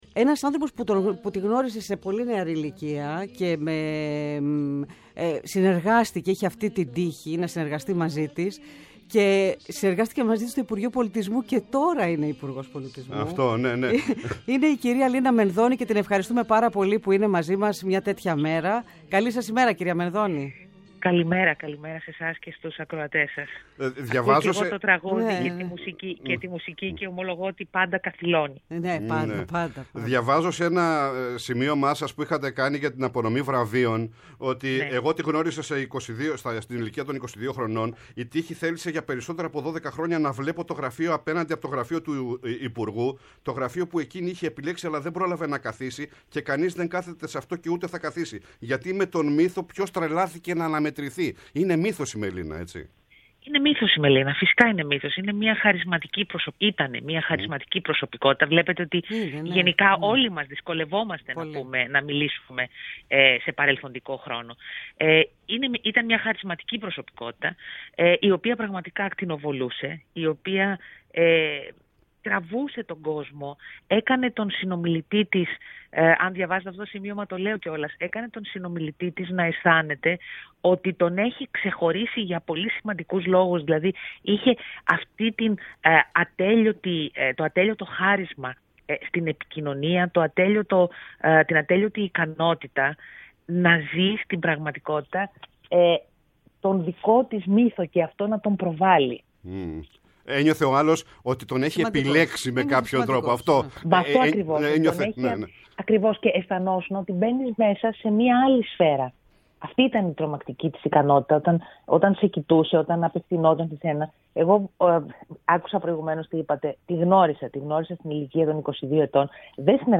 Η Υπουργός Πολιτισμού στο Πρώτο Πρόγραμμα | 06.03.2024